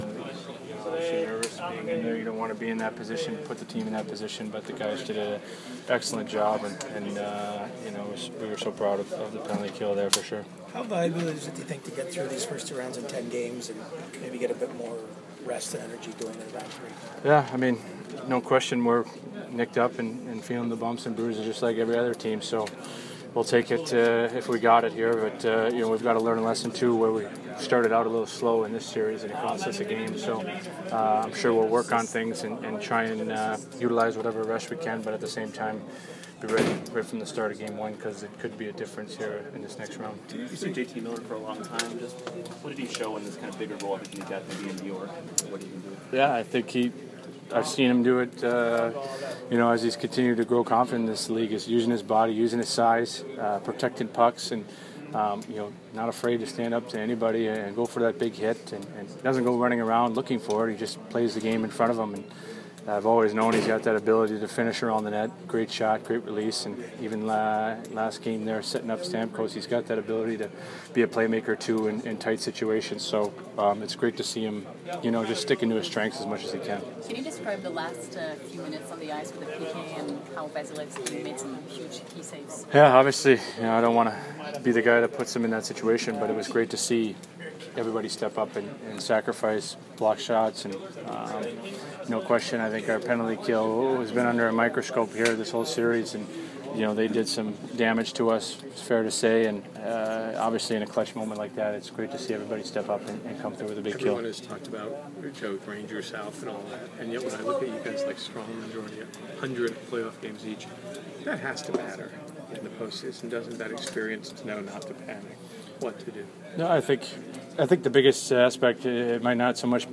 Ryan McDonagh post-game 5/6